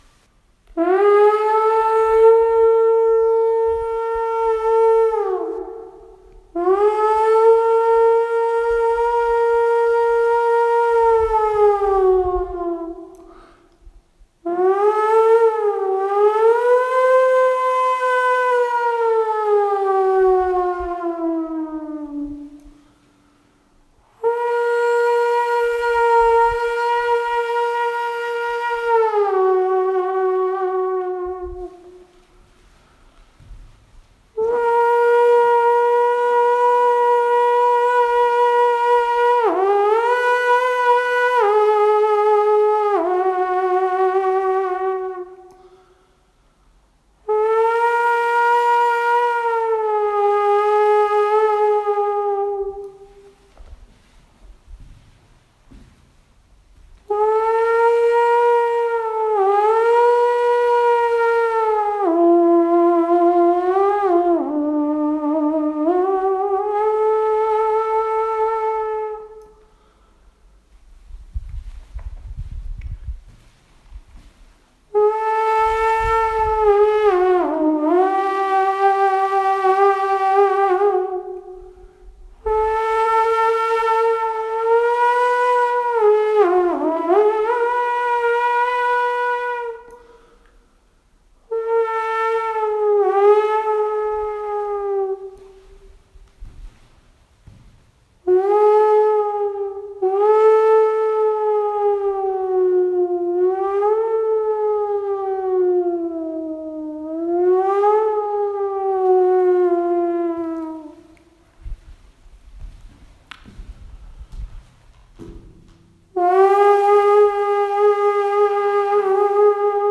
Eventually, I reached Egilsstaðir, which I think is the biggest town in the east, where I was able to refuel and then start my second diversion to Tvísöngur, a sound sculpture on the slope above the fjord and town of Seyðisfjörður.
Tvísöngur, which for the musically-inclined means twin-singing, is shown below.
Having no idea how to really “use” the sculpture and having absolutely zero musical ability (actually, can you have negative musical ability?), I tried making some noises with my throat while standing under each of the five domes.